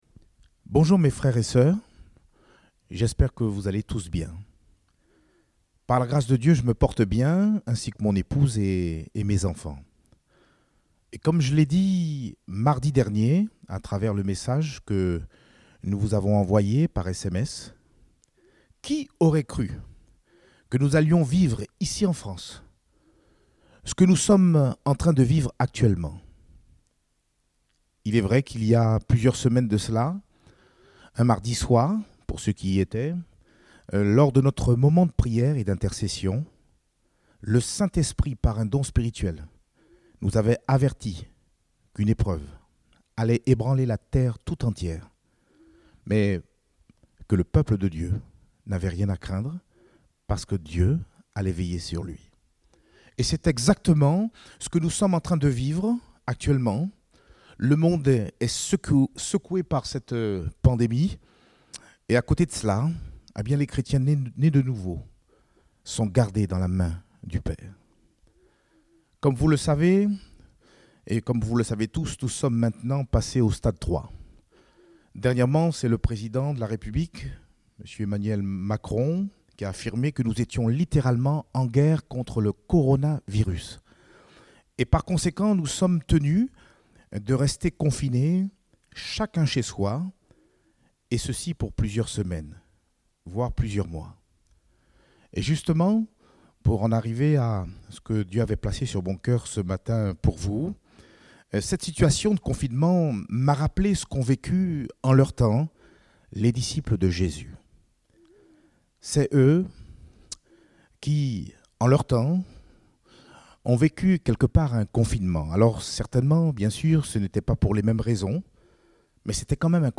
Date : 22 mars 2020 (Culte Dominical)